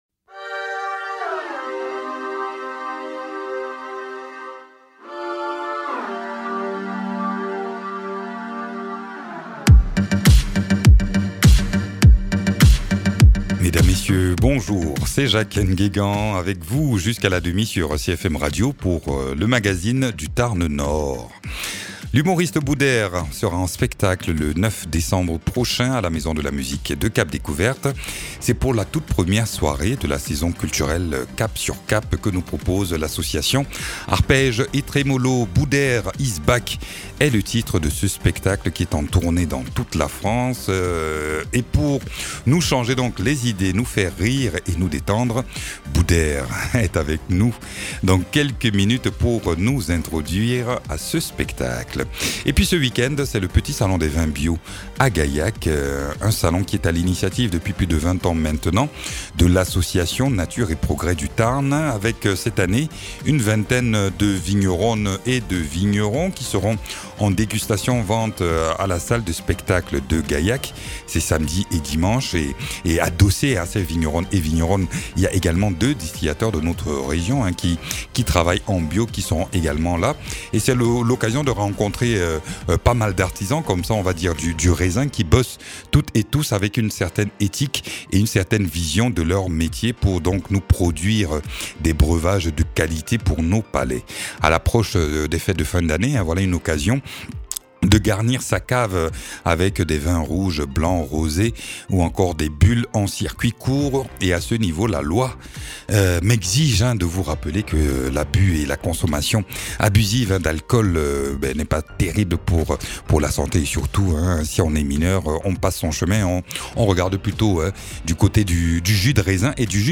Invité(s) : Booder, comédien et humoriste.